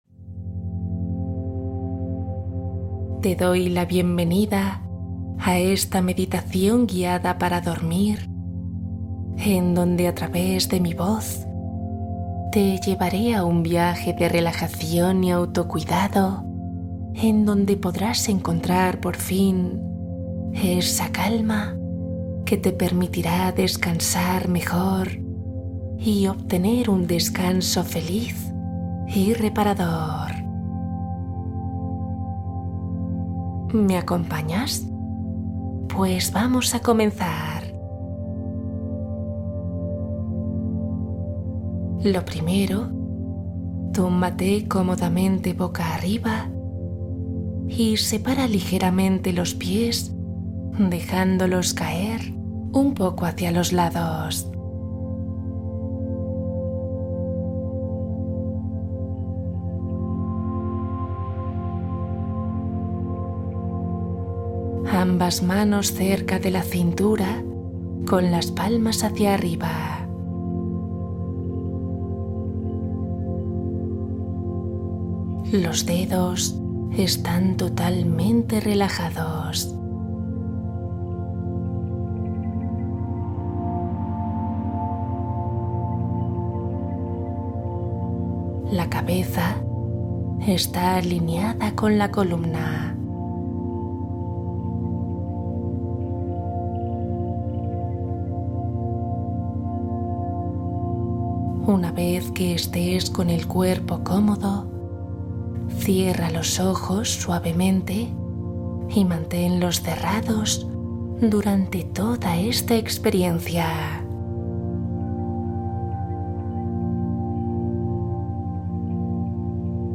Relajación profunda Meditación guiada para dormir profundamente